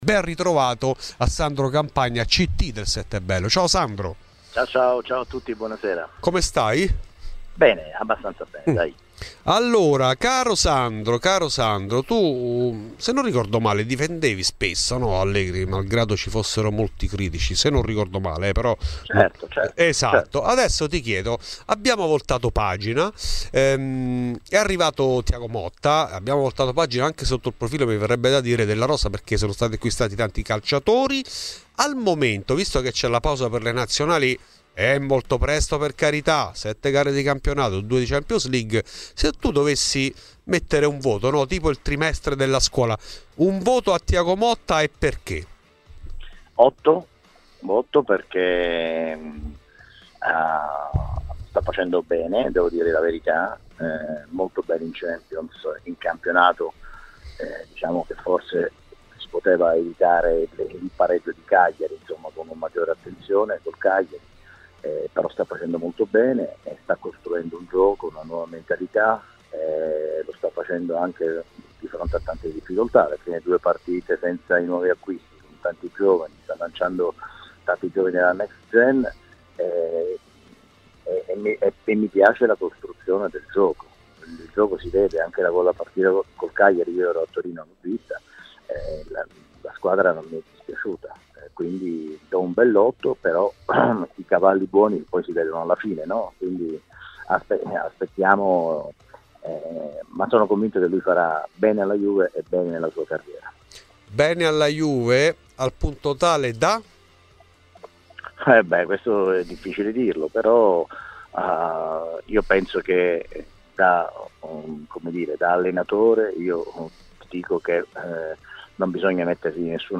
A Radio Bianconera, durante Fuori di Juve, è intervenuto uno juventino doc come Sandro Campagna, ct del Settebello di pallanuoto.